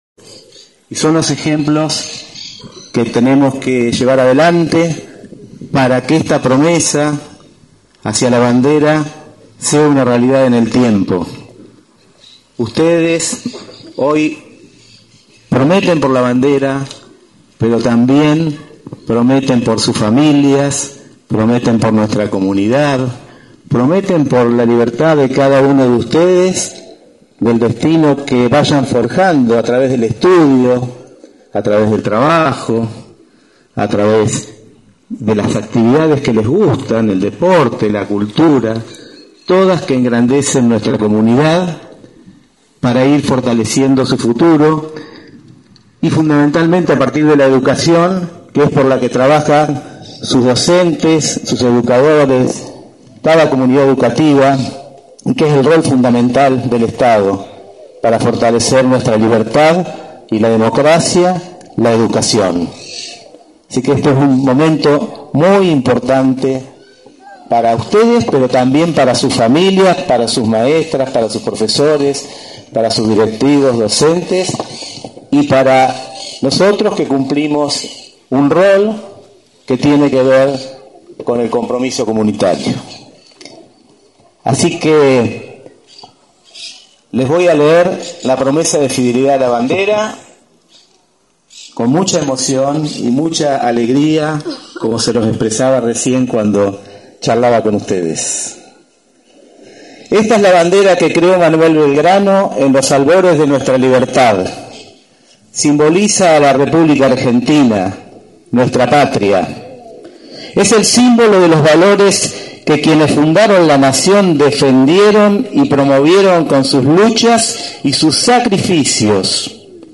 Finalizando, el intendente Ingeniero Alberto Gelené, tomó la promesa a los alumnos de 4to Grado de todas las instituciones educativas del partido de Las Flores.